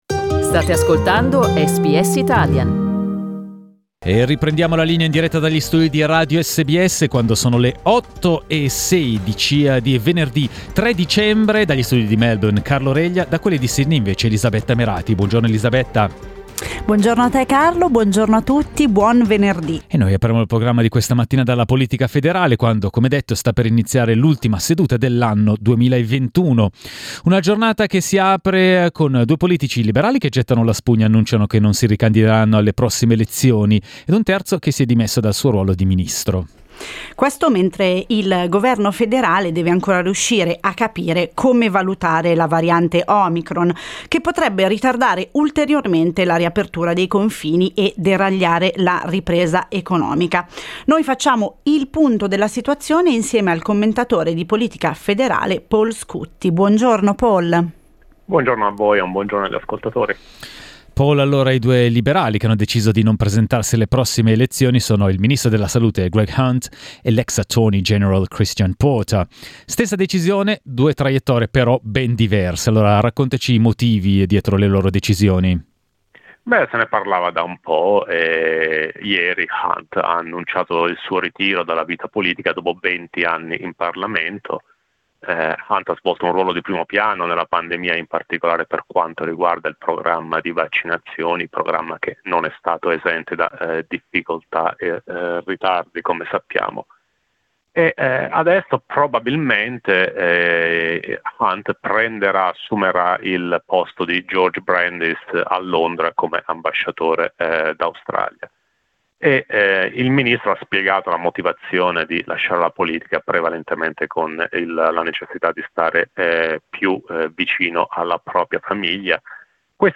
Il commentatore di politica federale